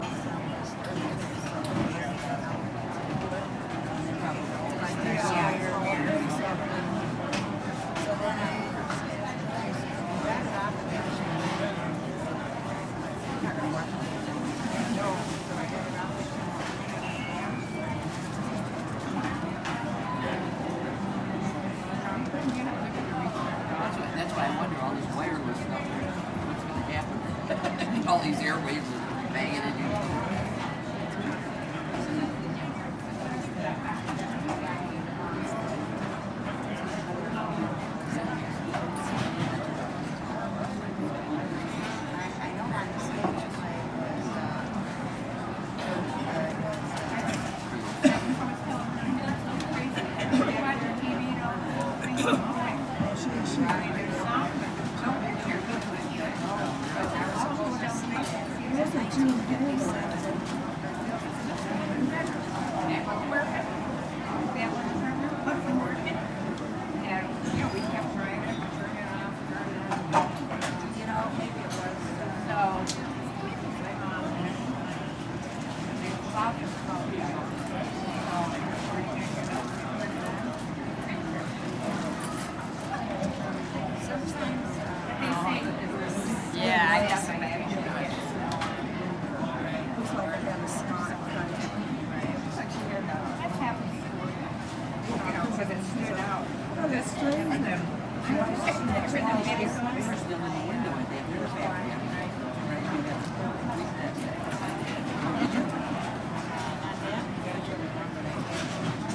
Coffee Shop.mp3